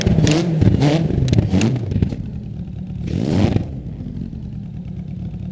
Index of /server/sound/vehicles/lwcars/renault_alpine
slowdown_highspeed.wav